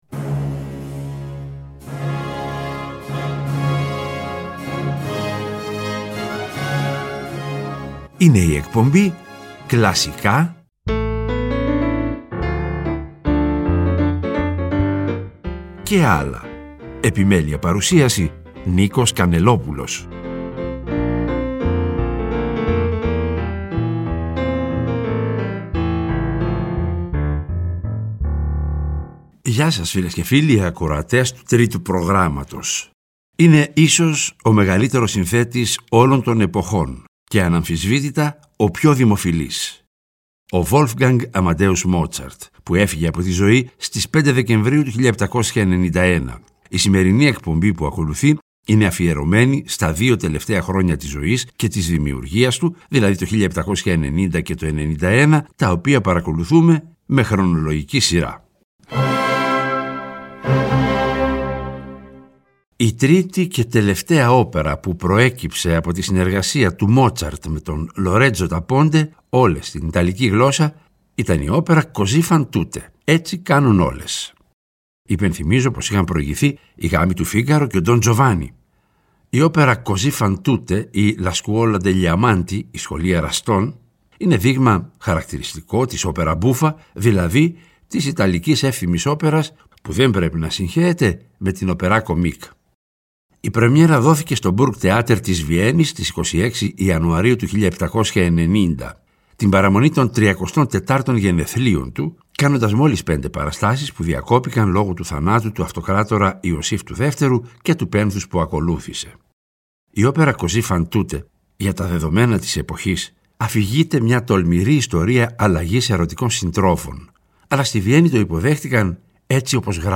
Και, προς το τέλος κάθε εκπομπής, θα ακούγονται τα… «άλλα» μουσικά είδη, όπω ς μιούζικαλ, μουσική του κινηματογράφου -κατά προτίμηση σε συμφωνική μορφή- διασκευές και συγκριτικά ακούσματα.